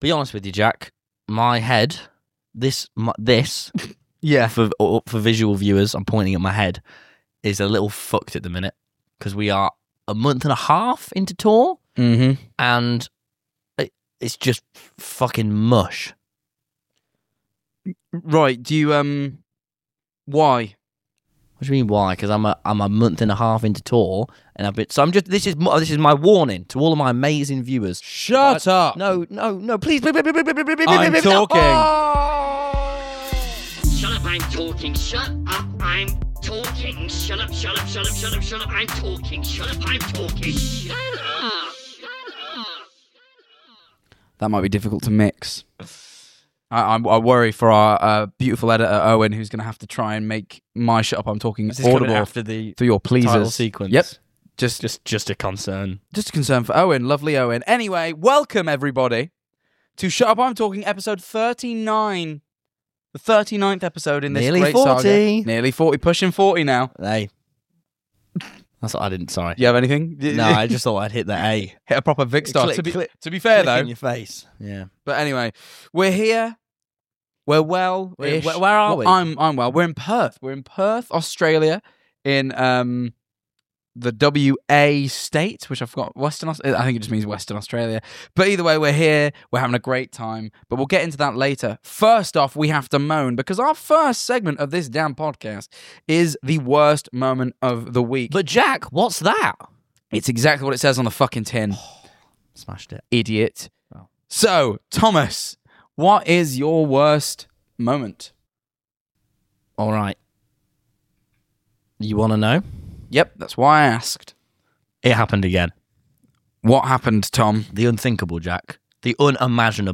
Written & presented by: Tom Simons & Jack Manifold
This episode was recorded on 18/05/25 in Perth